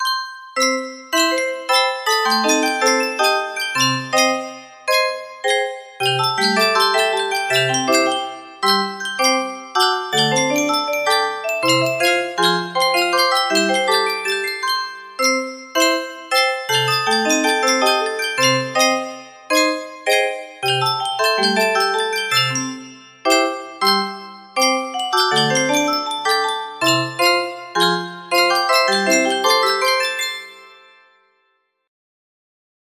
Full range 60
Arranged THICK like lol